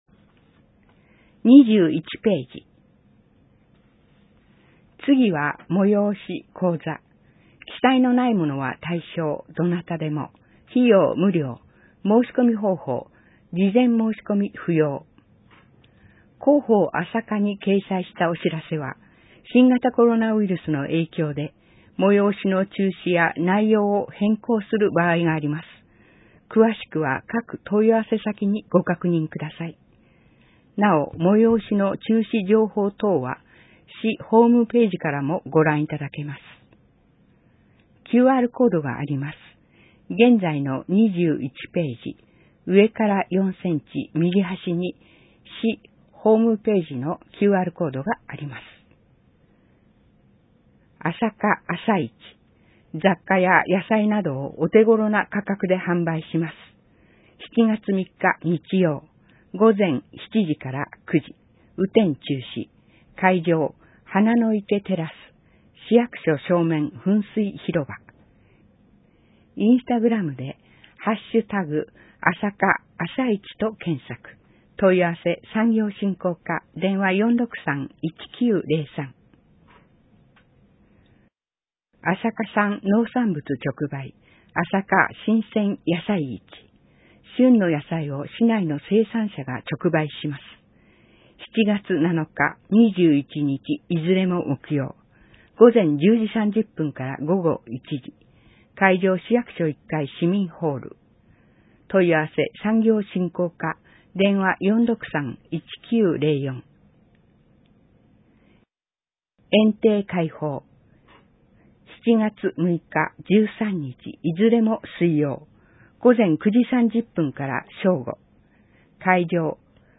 埼玉県朝霞市が発行している広報あさか6月号を、リーディングサークルさんの協力で、音声にしていただいたものです。